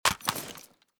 ak74_draw.ogg.bak